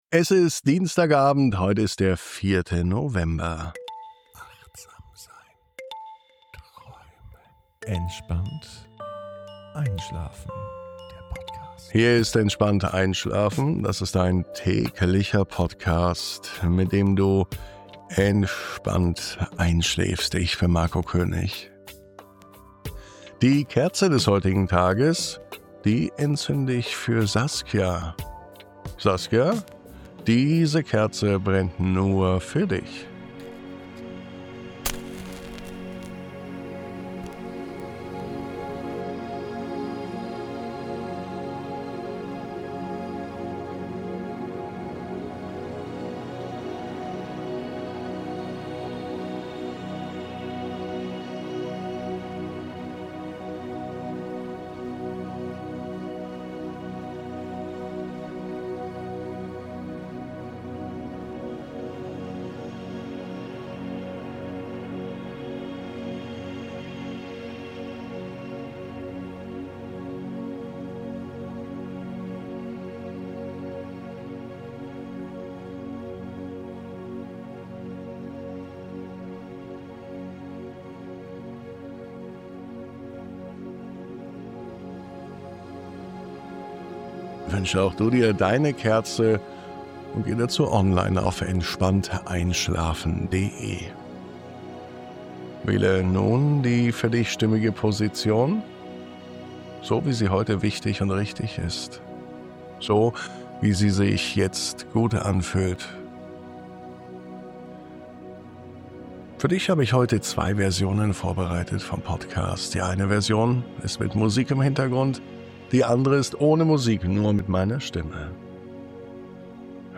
Di, 04.11. Entspannt einschlafen - Stille im Kopf, Ruhe im Herz ~ Entspannt einschlafen - Meditation & Achtsamkeit für die Nacht Podcast